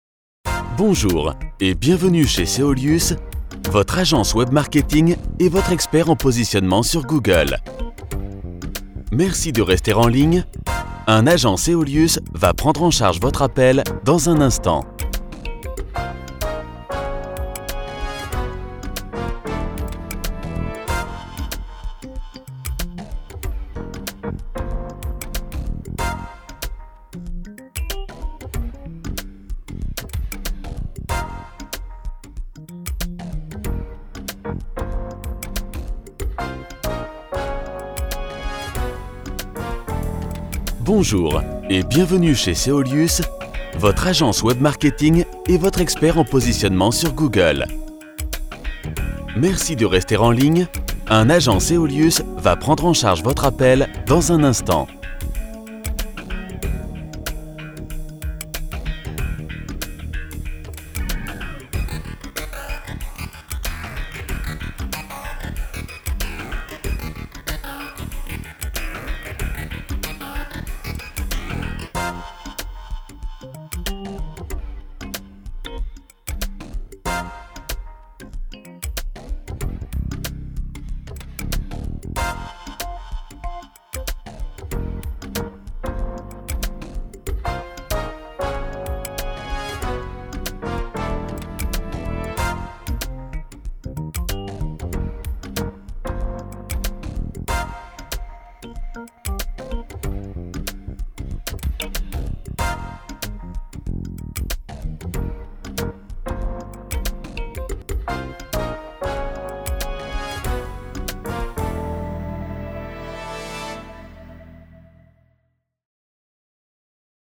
Polyvalente, Naturelle, Cool, Profonde, Chaude
Téléphonie